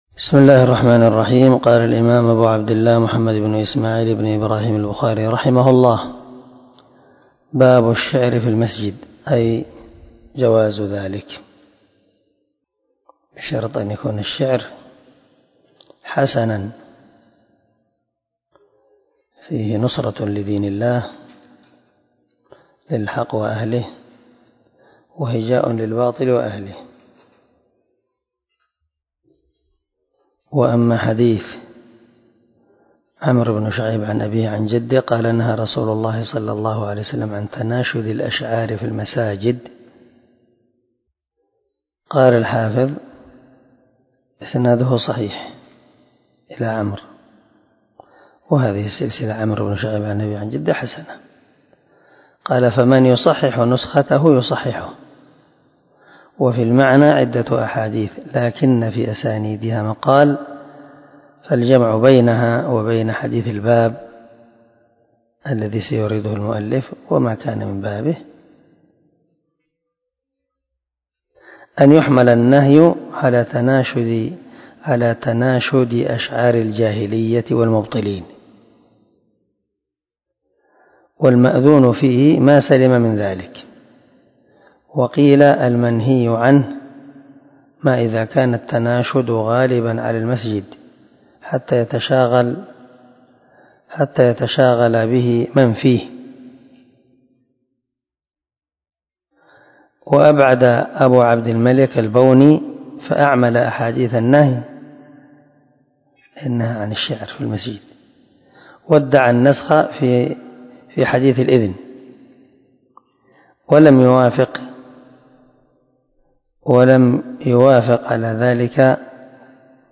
337الدرس 70 من شرح كتاب الصلاة حديث رقم ( 453 ) من صحيح البخاري